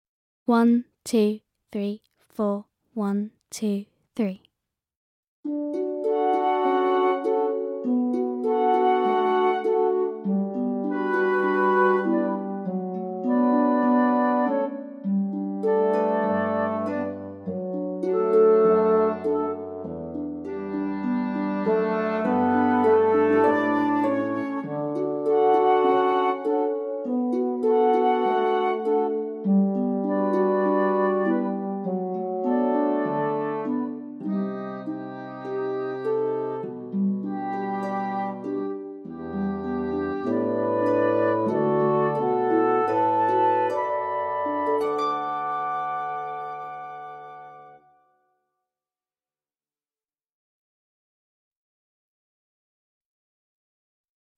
VS On the River (backing track)